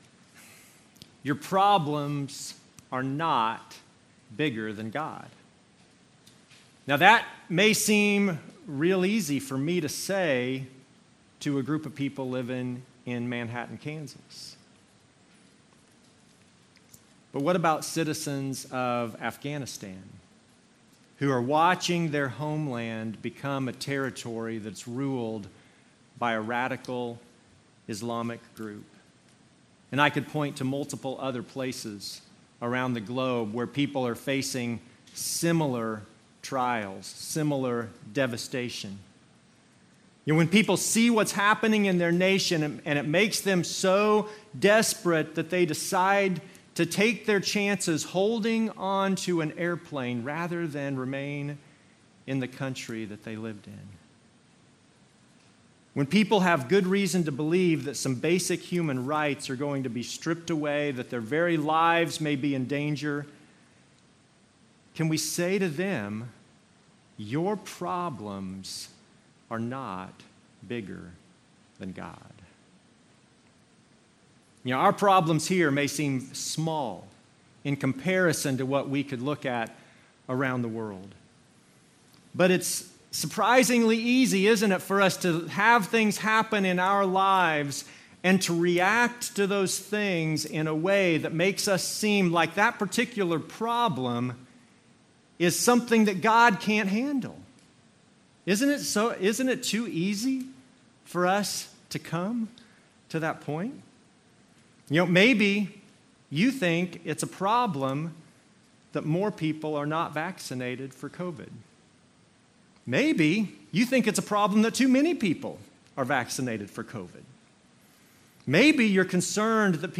Passage: Exodus 1:1-7 Service Type: Normal service